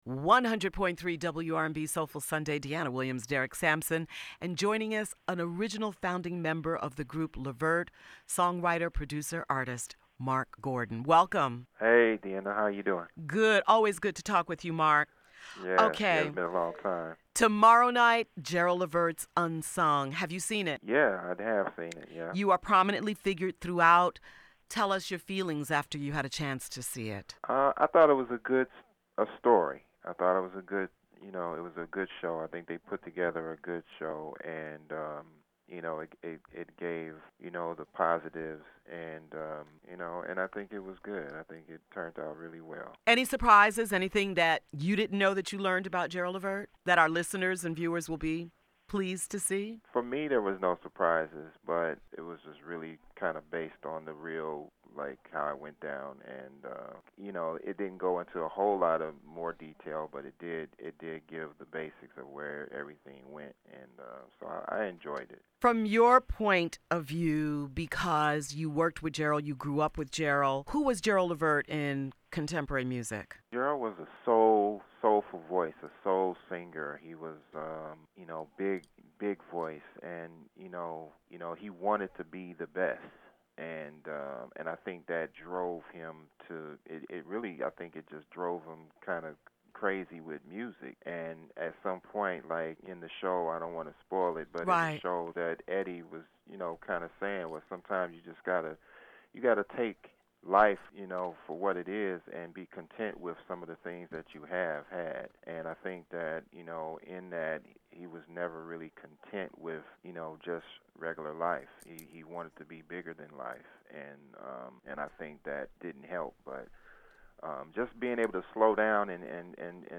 Marc Gordon From “Levert” – UnSung Interview (AUDIO)